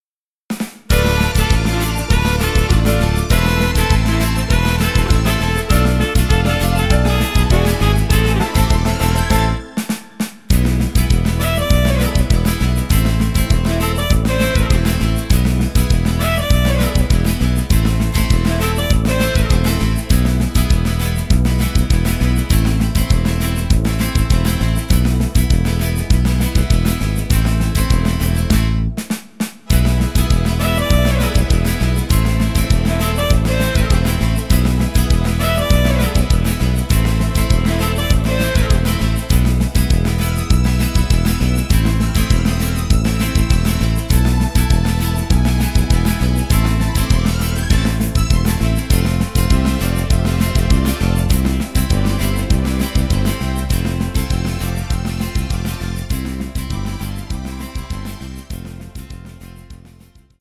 ein richtig guter Twist